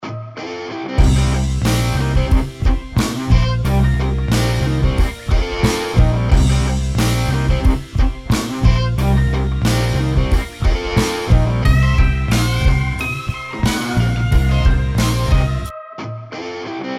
BluesRock-Klingelton